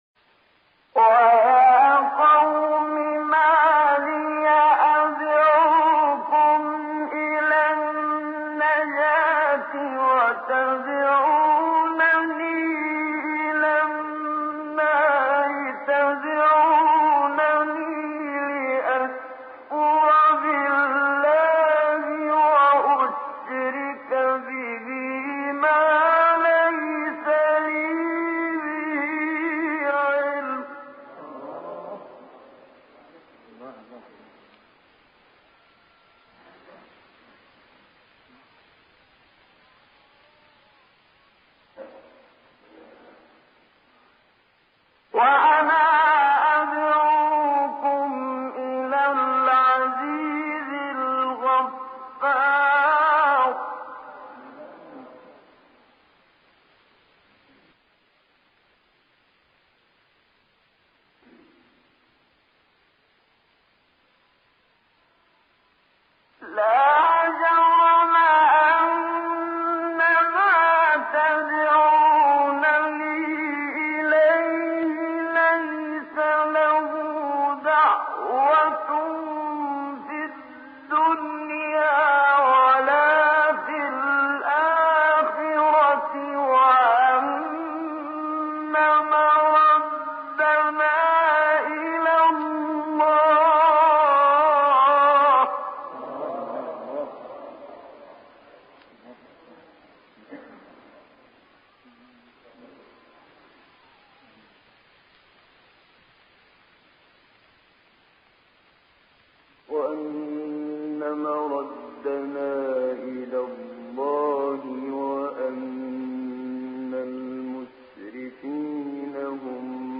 پس با یک سوز و گداز درونی آیه تلاوت می‌شود و نشان می‌دهد که دعوت‌کننده دلسوز قوم خود است و حال آنکه آن‌ها به چیز دیگری تمایل دارند.
منشاوی این بخش از تلاوت خود را با تلاوت در پرده بم به اتمام می‌رساند؛ یعنی بخش پایانی آیه 43 غافر را با پرده بم تلاوت می‌کند تا به نوعی نشان دهد که در نهایت دعوت‌کننده از دعوت قومش ناامید شده است؛ هرچند که دلسوزی او به قوت خود باقی است.